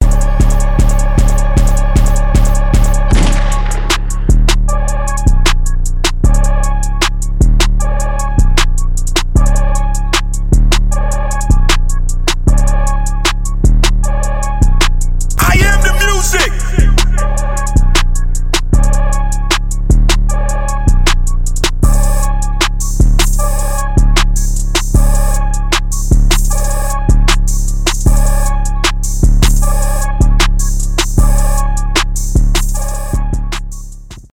trap , басы , рэп
четкие , качающие